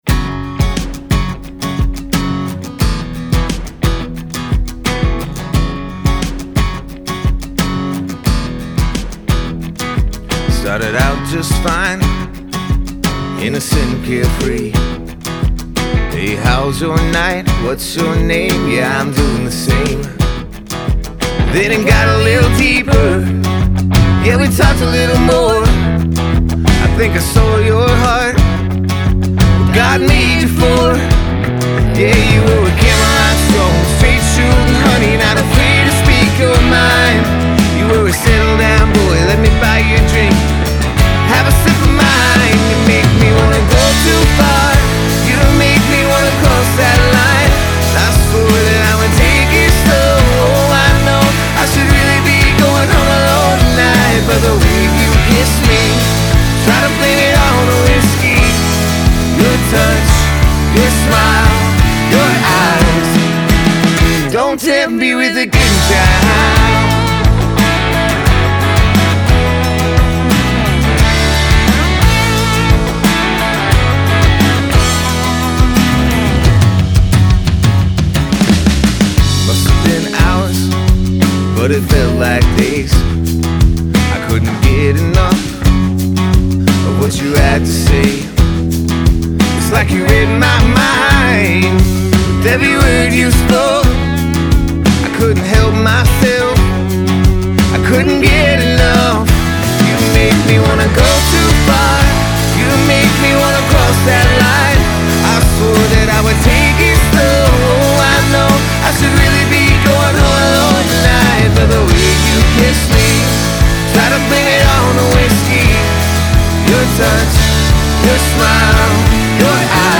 Charleston’s five-piece band